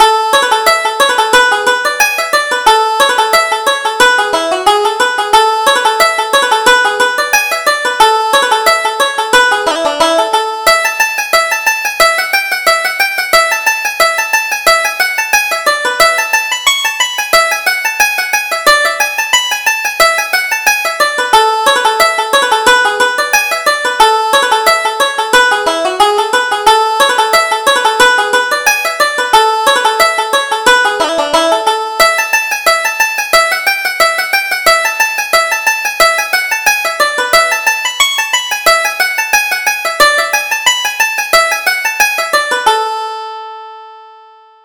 Reel: The Green Groves of Erin